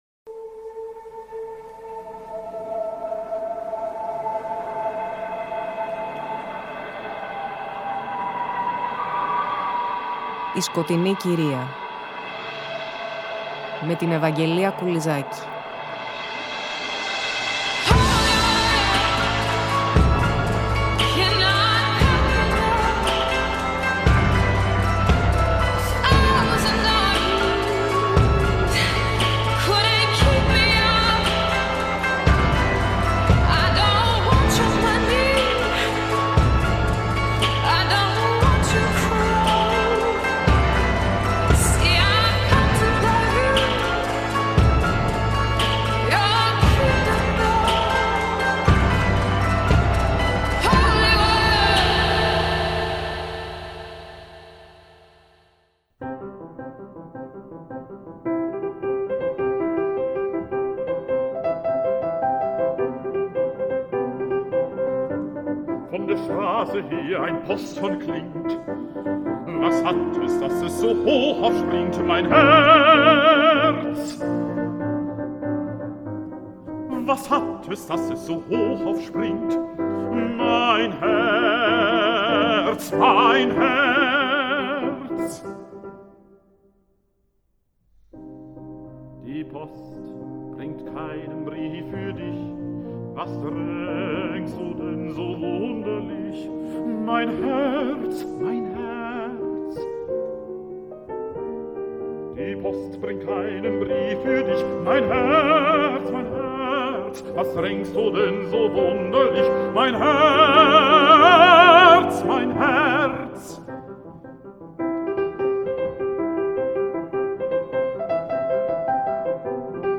Πλαισιώνουμε μουσικά με κομμάτια από το ” Winterreise ”, τον κύκλο τραγουδιών του Franz Schubert σε ποίηση Wilhelm Müller .